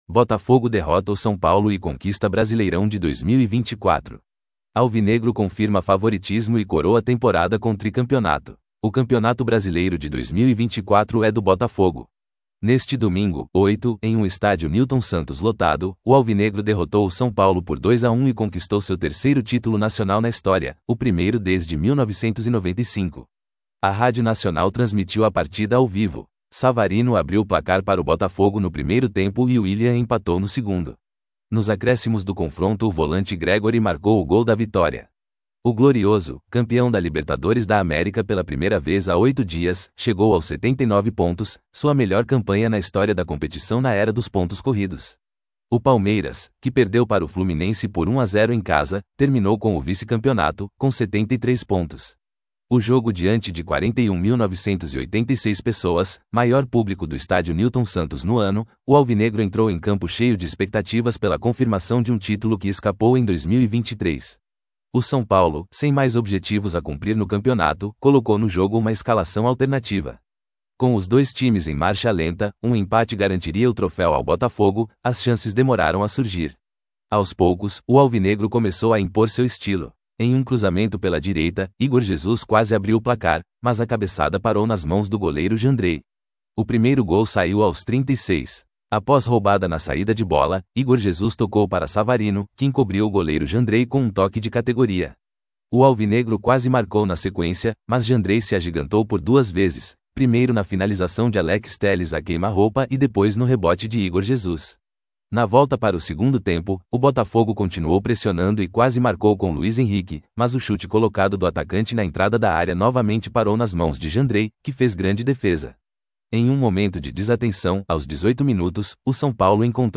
A Rádio Nacional transmitiu a partida ao vivo.